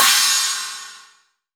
CHINA.WAV